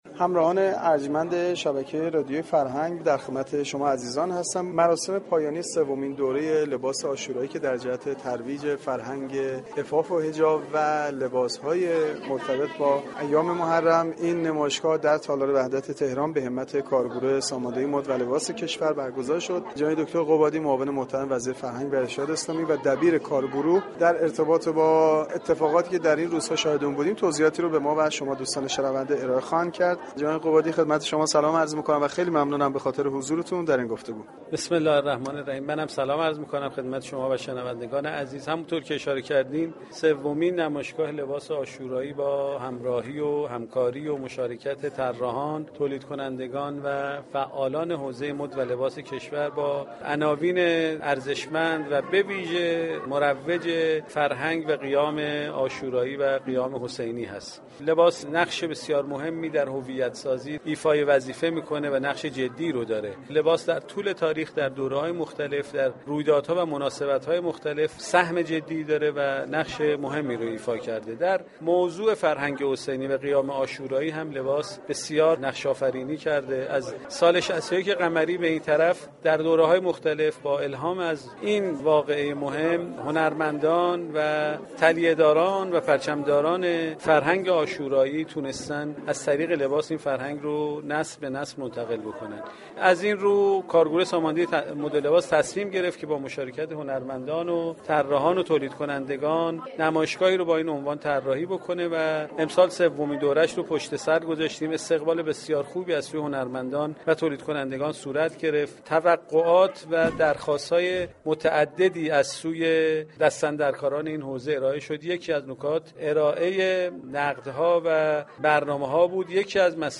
حمید قبادی معاون وزیر فرهنگ و ارشاد اسلامی و رییس كارگروه مد و لباس كشور در گفتگوی اختصاصی با گزارشگر رادیو فرهنگ گفت : لباس نقش بسیار مهمی در هویت سازی ایفا می كند ، در طول تاریخ و دوره های مختلف ، در رویدادها و مناسبتهای مختلف ، لباس سهم جدی و مهمی را ایفا كرده است .